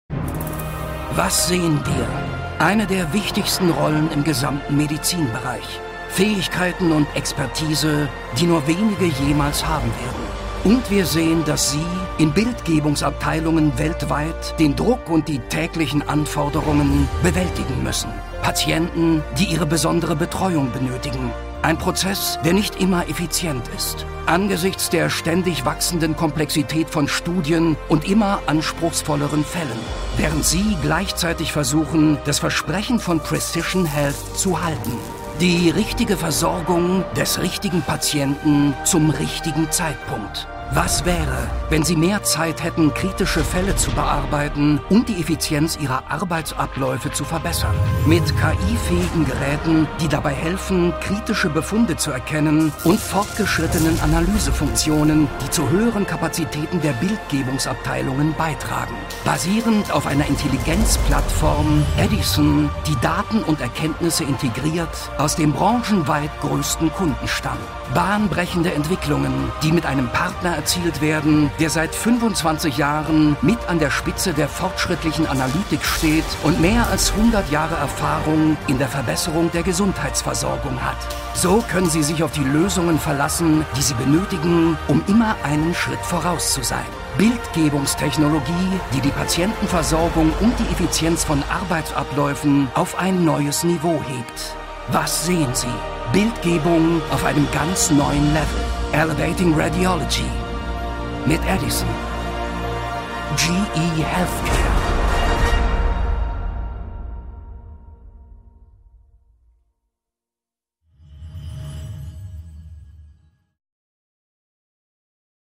Professionell und erfahren: deutscher Schauspieler und Sprecher für Werbung, Imagefilm, Erklärfilm, e-Learning, Hörbuch, Voiceover
Sprechprobe: Industrie (Muttersprache):
Experienced German Voice Artist and Actor.